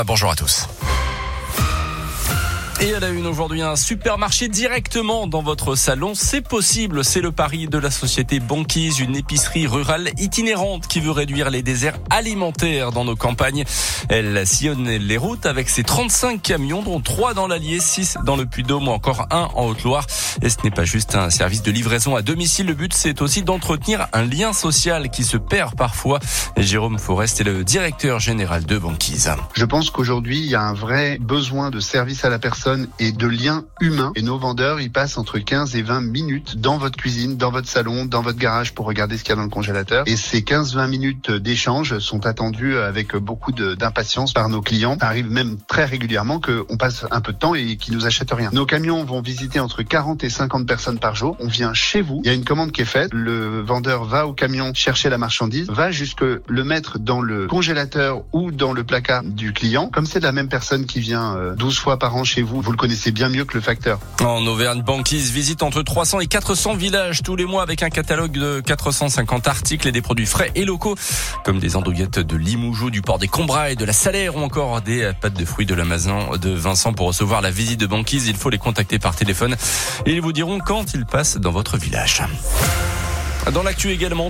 Banquiz a récemment été mis à l'honneur sur Radio Scoop, avec une intervention diffusée dans plusieurs flashs info de la matinale.